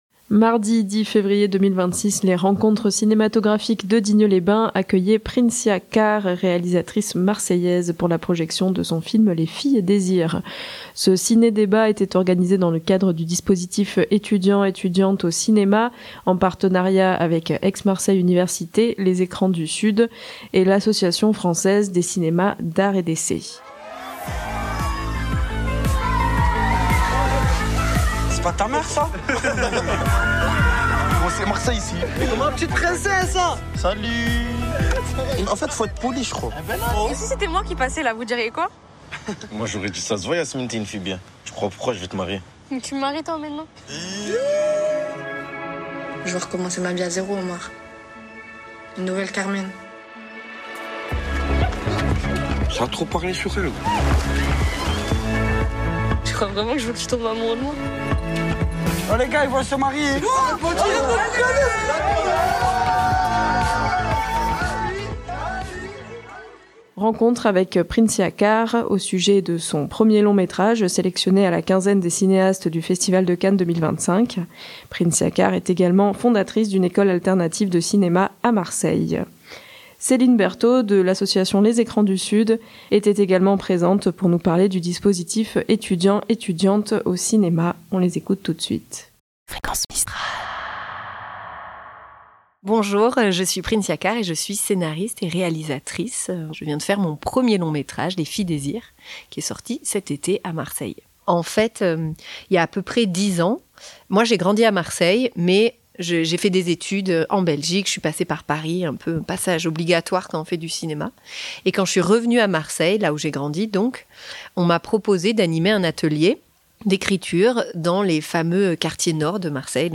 Trouver son identité individuelle au sein d'un groupe groupe, mettre en valeur la joie et la couleur des cœurs dans les quartiers nord de Marseille, ou encore questionner la place du libre-arbitre des femmes font partie des enjeux évoqués dans cet entretien.
Extrait de la bande-annonce du film Les Filles désir